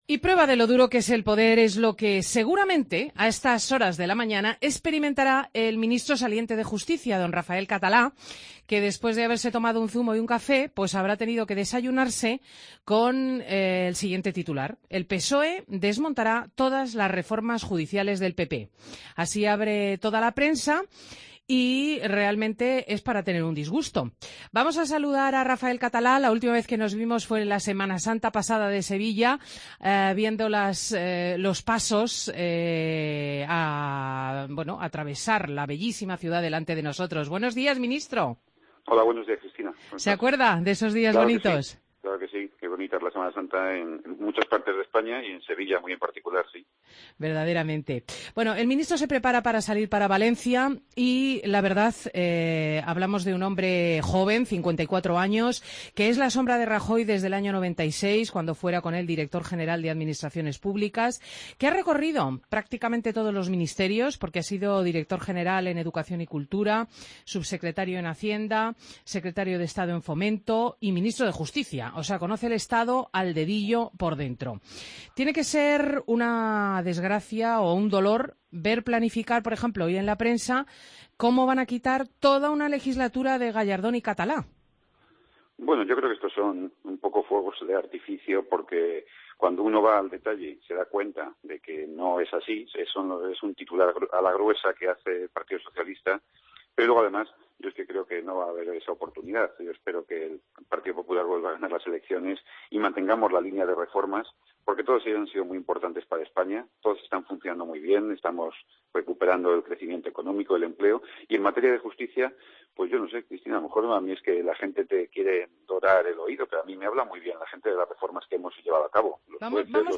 Entrevista al ministro de Justicia, Rafael Catalá, en Fin de Semana